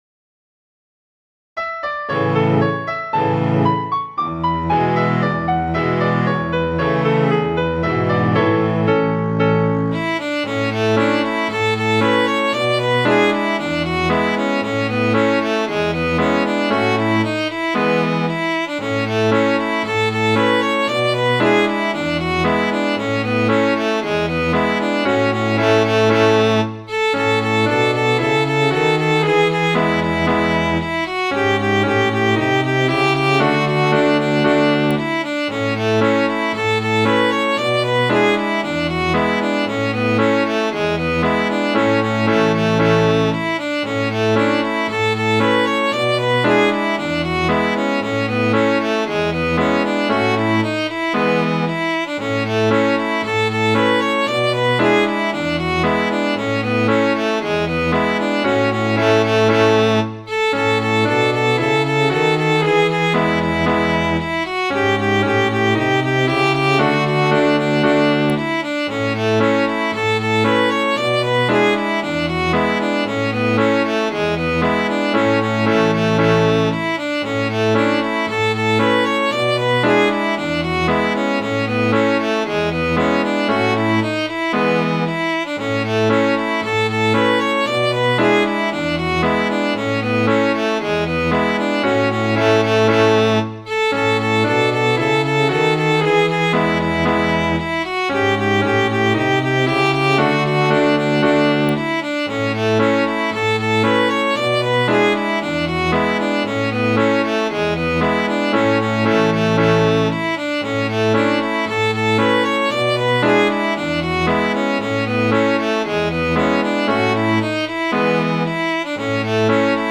Midi File, Lyrics and Information to Grand Conversation of Napoleon